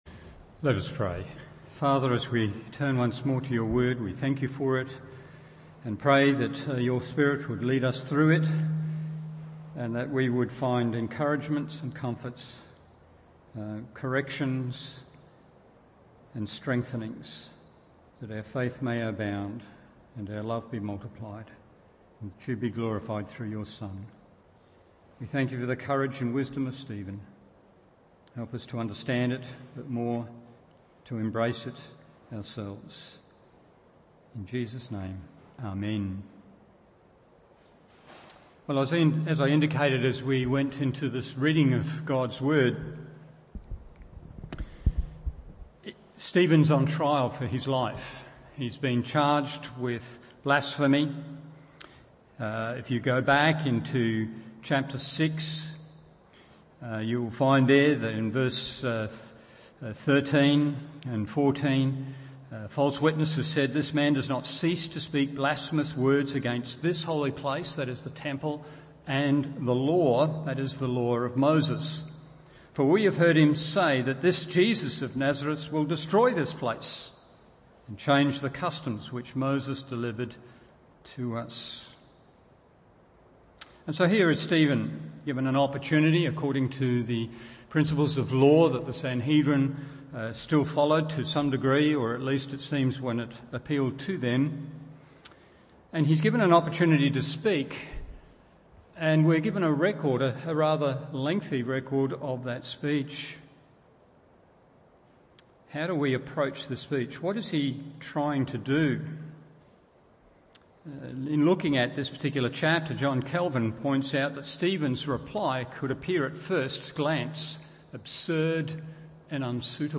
Evening Service Acts 7:1-53 1.